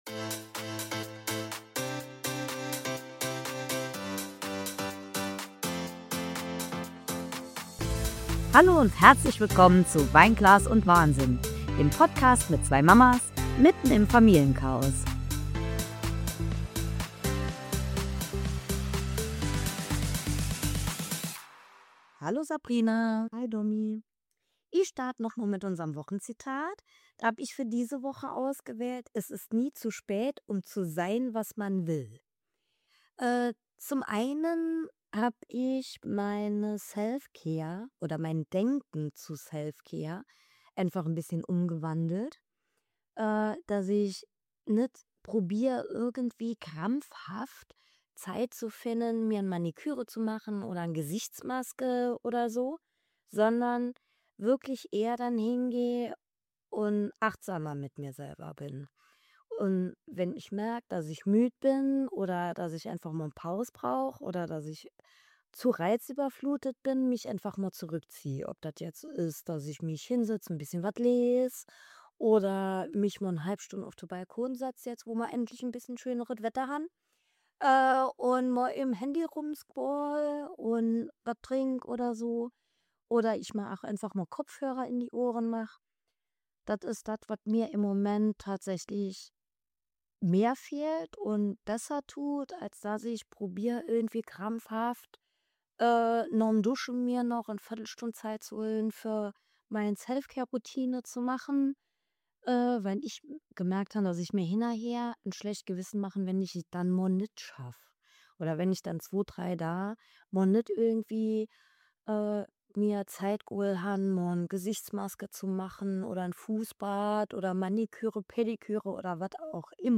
In dieser herrlich chaotischen Frühlingsfolge nehmen wir euch mit zwischen Vogelgezwitscher, Kaffeeduft und latentem Familienwahnsinn.